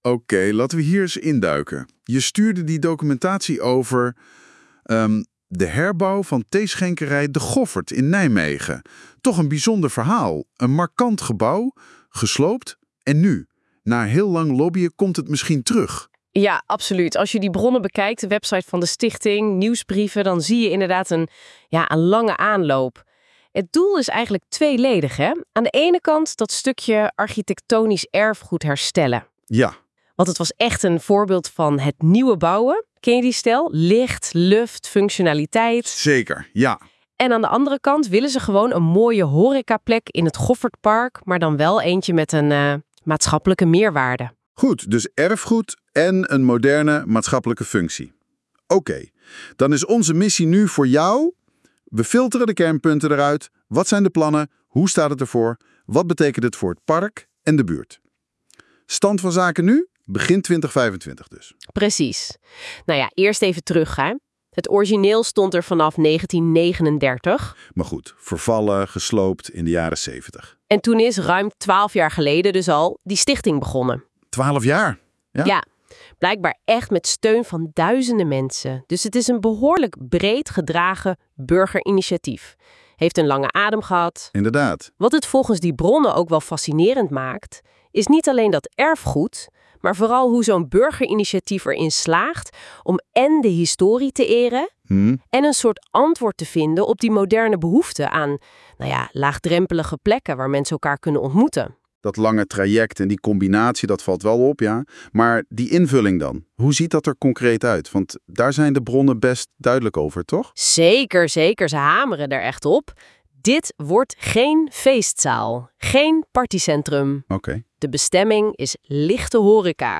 hier de podcast van NotebookLM (AI tool) over de Theeschenkerij.